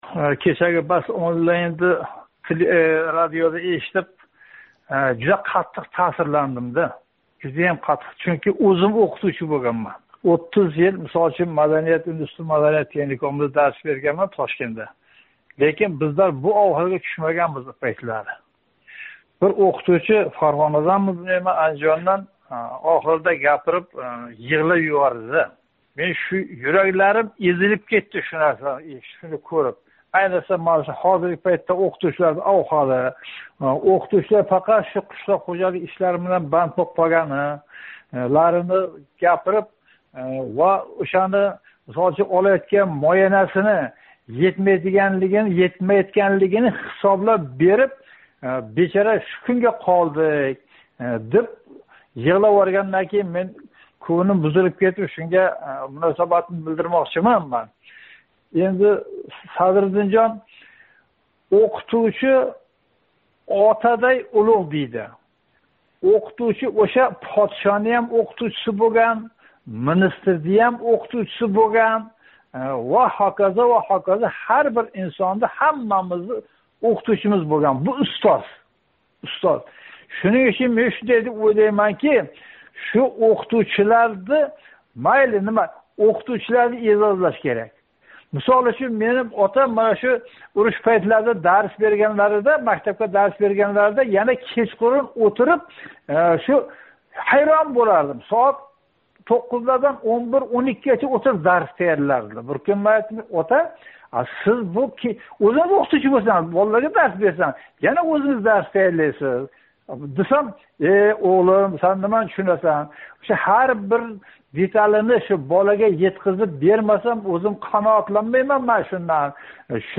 OzodMikrofon га қўнғироқ қилган ўзбекистонликлар уларнинг розилигини олмасдан туриб, пенсия жамғармаси пенсия пулларини юз фоиз пластик карточкага ўтказиб юбораётгани, мамлакатда коррупция авж олгани, ўқитувчиларнинг бугунги оғир аҳволи ҳақида гапирдилар.
суҳбат